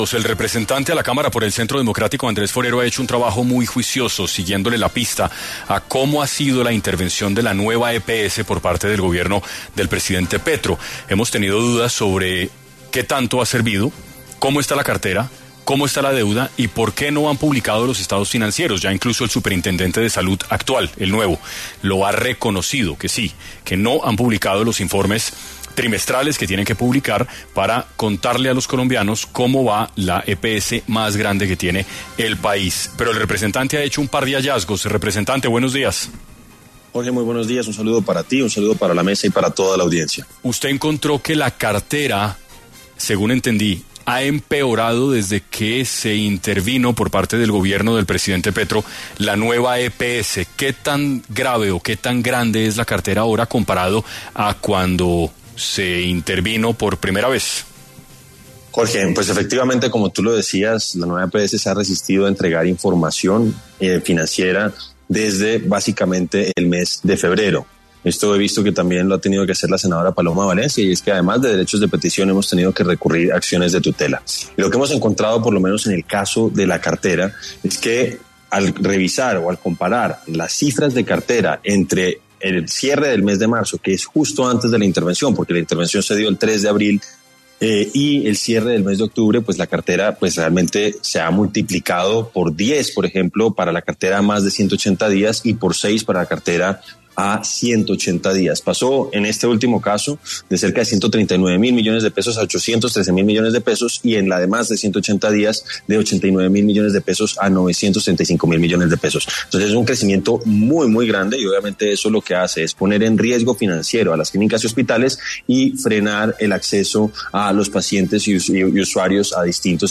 En Caracol Radio estuvo Andrés Forero, representante a la Cámara, conversando sobre las intervenciones que ha realizado el Gobierno Petro a las EPS